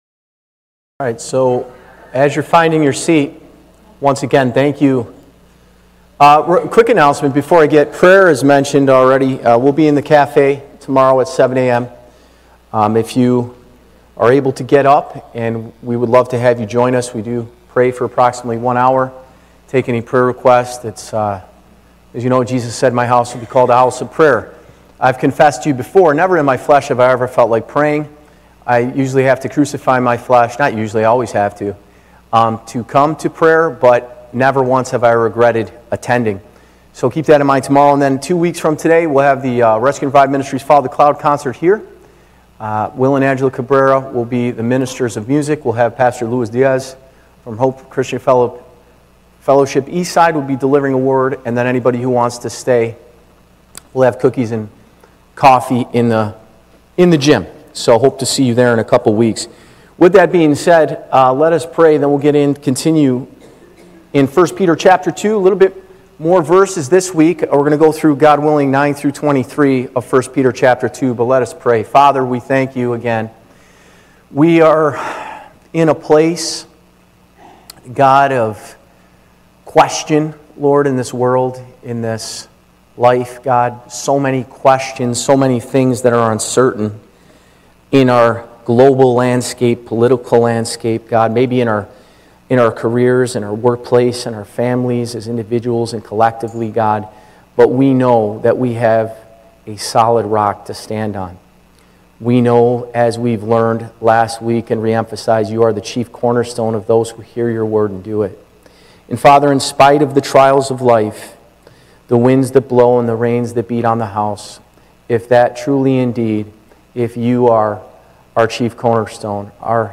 1 Peter 2:9-25 Live Recording Date